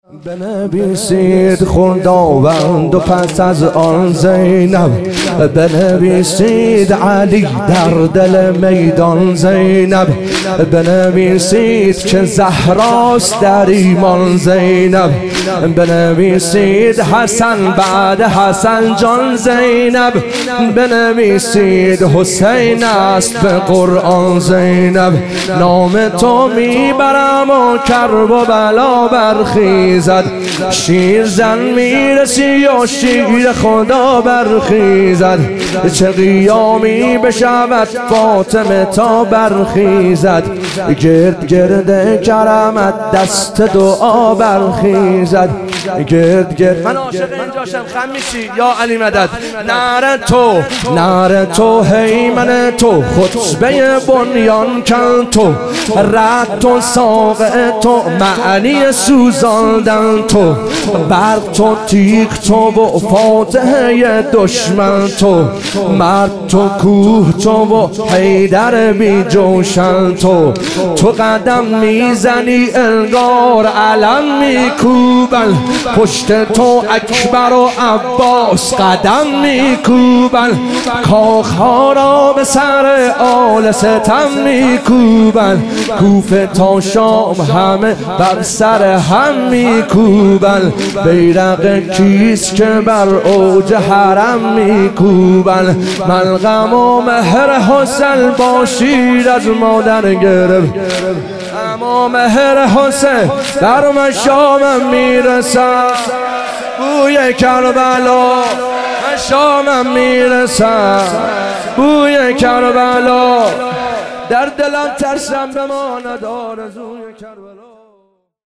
ظهور وجود مقدس حضرت زینب علیها سلام - واحد